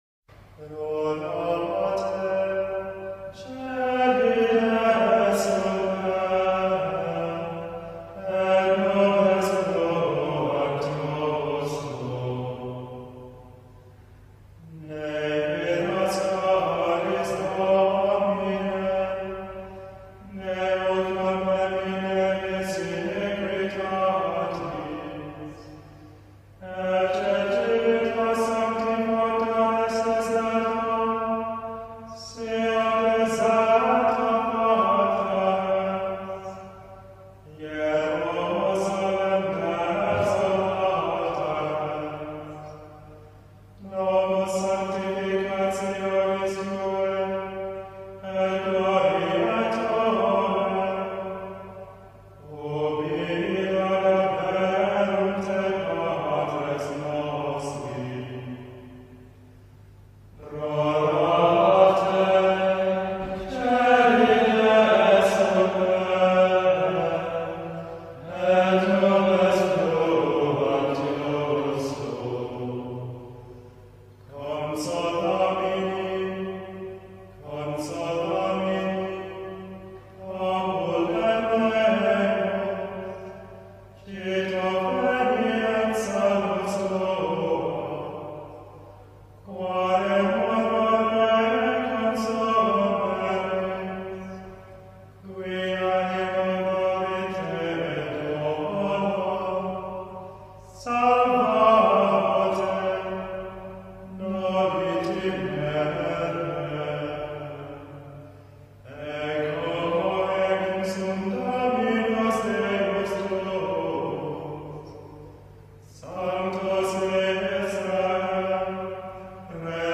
Audio di un canto gregoriano Rorate cœli desuper è il titolo dell’Introito della messa della quarta domenica di Avvento e del comune della Beata Vergine Maria.
canto_gregoriano.mp3